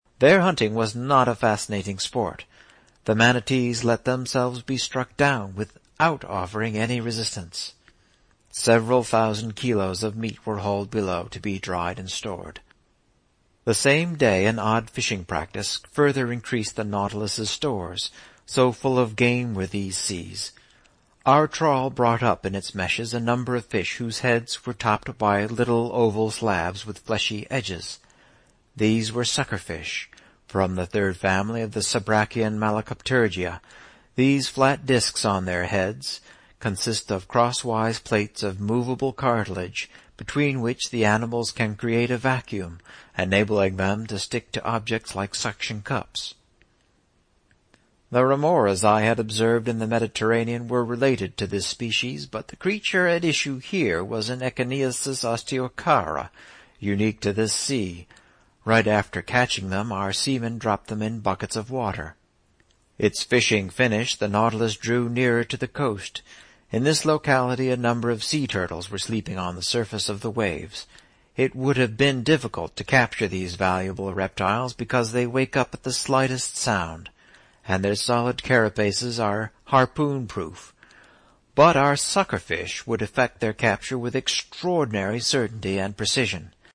英语听书《海底两万里》第491期 第30章 从合恩角到亚马逊河(14) 听力文件下载—在线英语听力室
在线英语听力室英语听书《海底两万里》第491期 第30章 从合恩角到亚马逊河(14)的听力文件下载,《海底两万里》中英双语有声读物附MP3下载